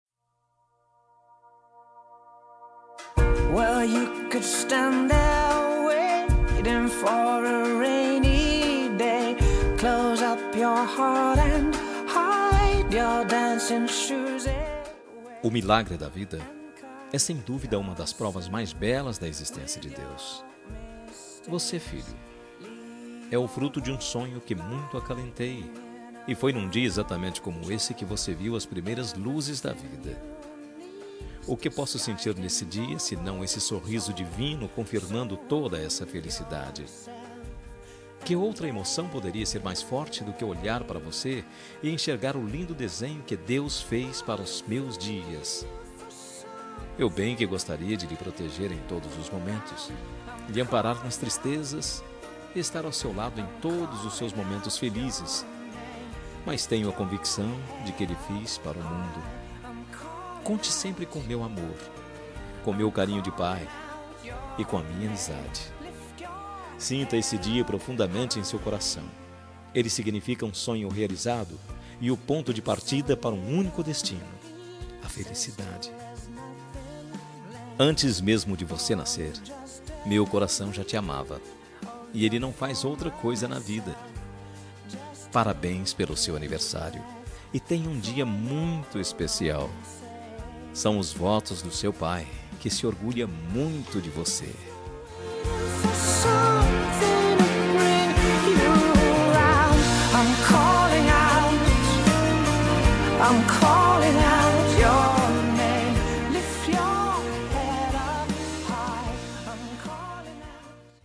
Temas com Voz Masculina